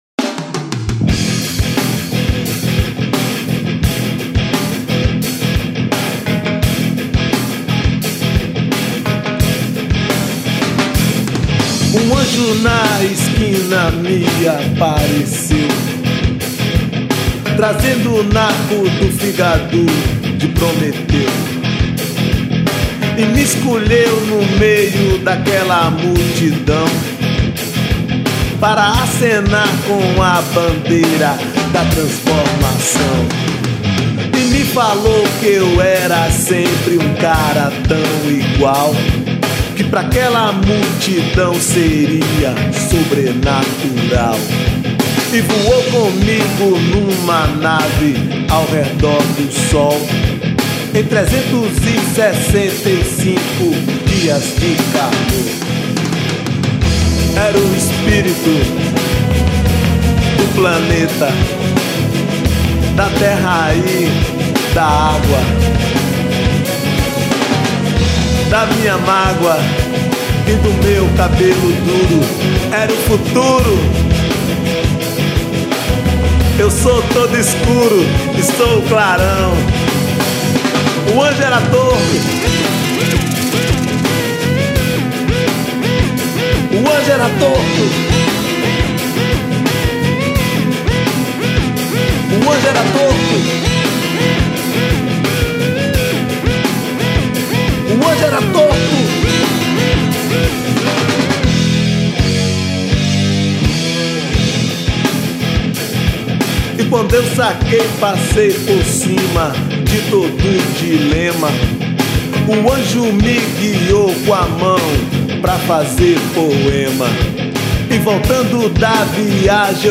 1340   05:08:00   Faixa:     Rock Nacional